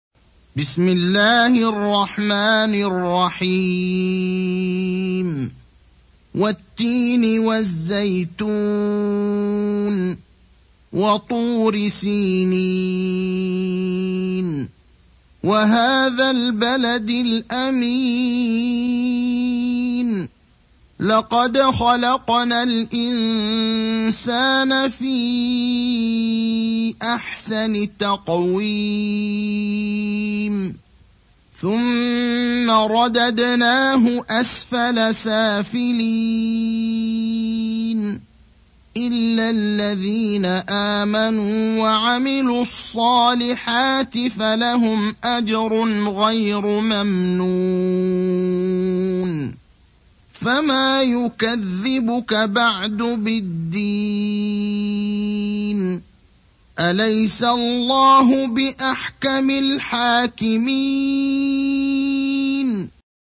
Récitation par Ibrahim Al Akhdar